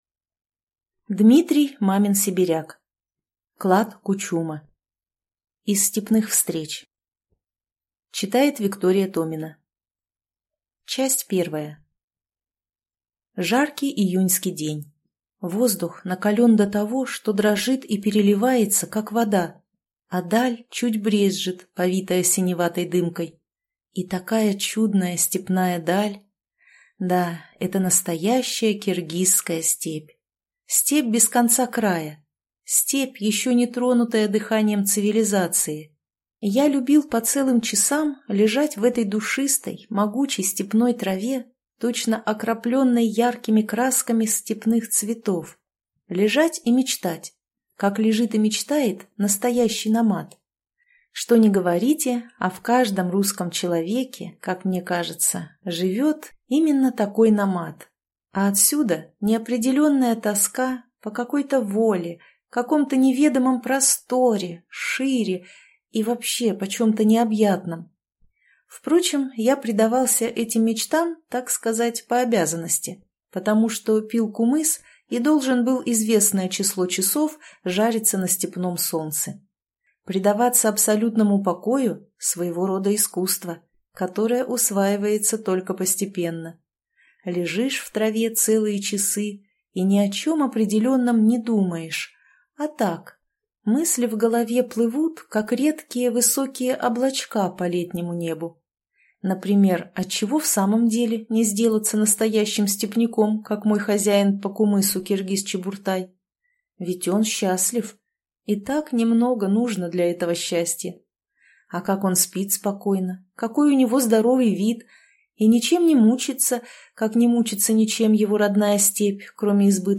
Аудиокнига Клад Кучума | Библиотека аудиокниг